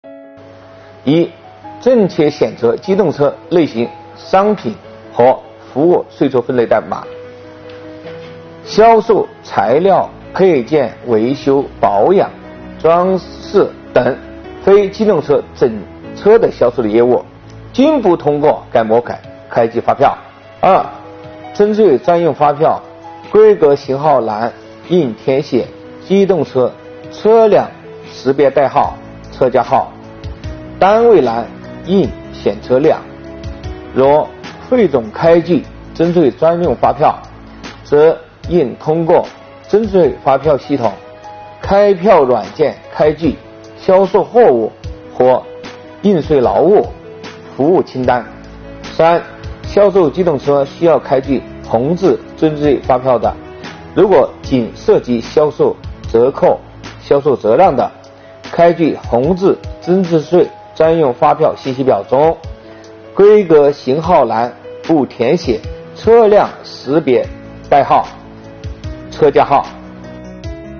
近日，由国家税务总局货物和劳务税司副司长张卫担任主讲的最新一期税务讲堂围绕《办法》相关政策规定，进行了详细解读。